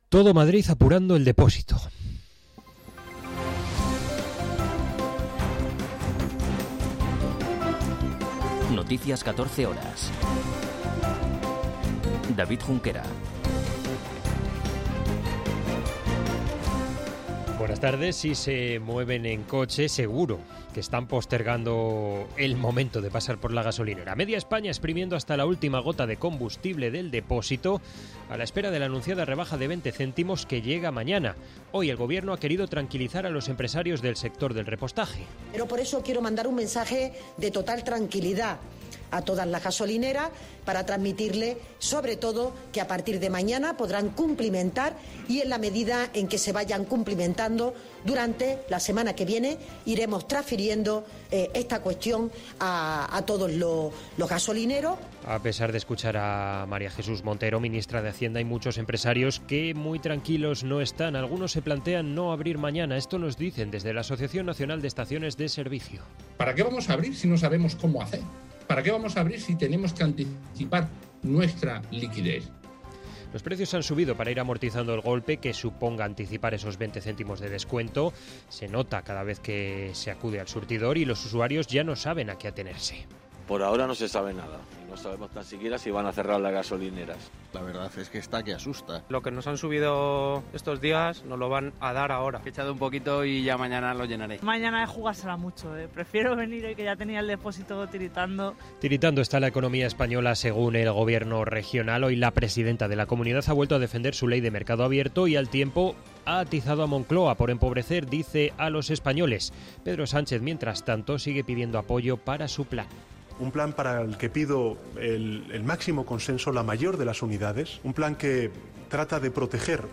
Noticias 14 horas 31.03.2022